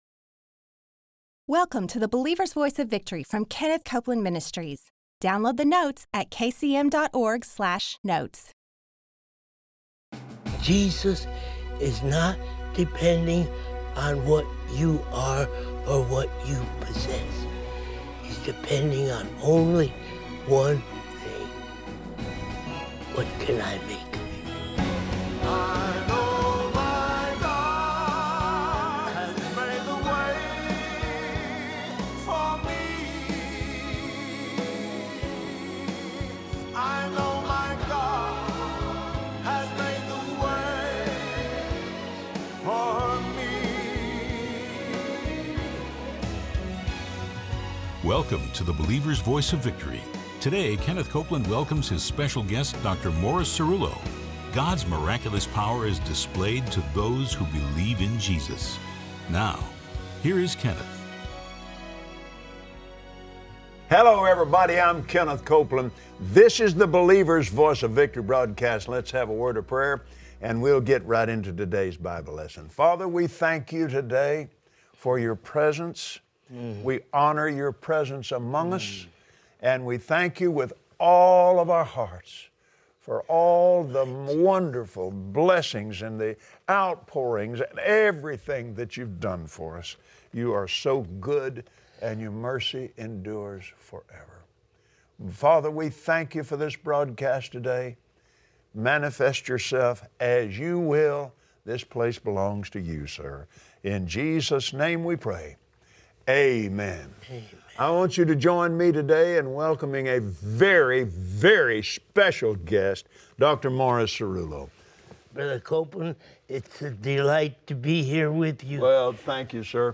Believers Voice of Victory Audio Broadcast for Monday 03/16/2017 Watch Kenneth Copeland and Dr. Morris Cerullo on the BVOV broadcast share how God can use the most unlikely people to do His will. It’s time to walk out God’s calling on your life.